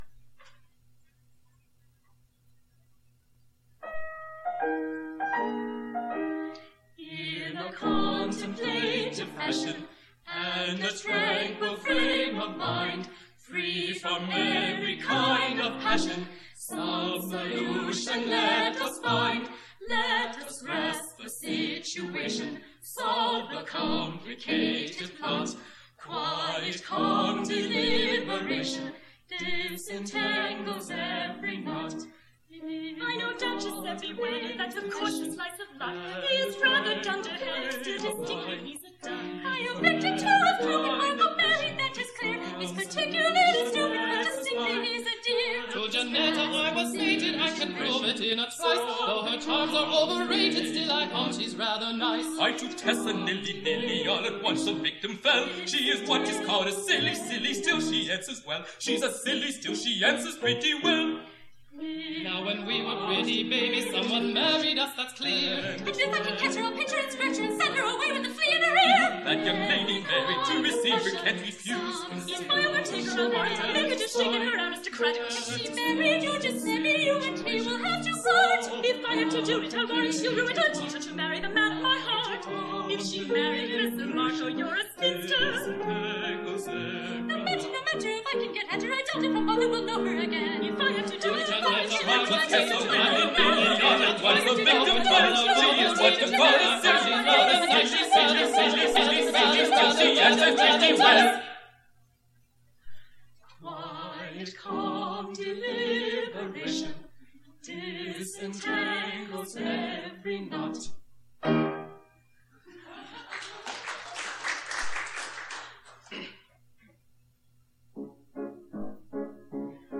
A recording has recently surfaced of our singing a concert in Ann Arbor Michigan. You can listen to a sample of that recital below.